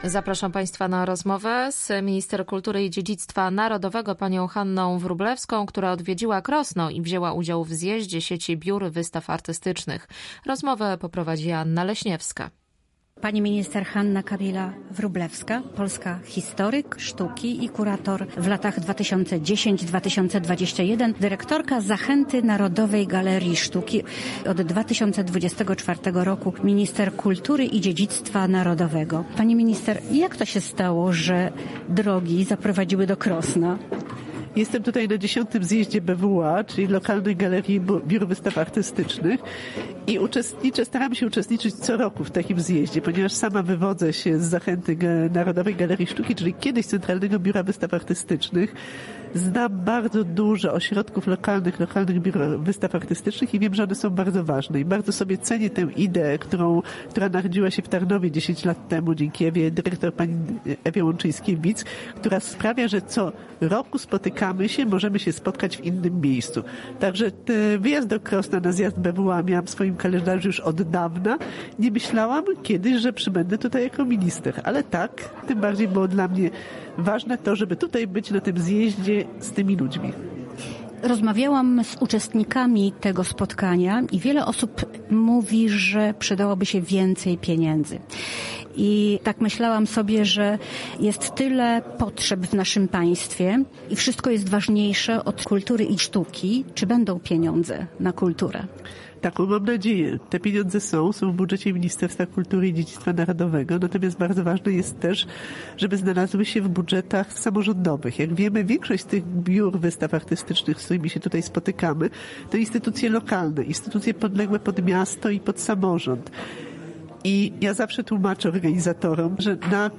Minister podkreśliła w rozmowie z Polskim Radiem Rzeszów znaczenie tego typu instytucji.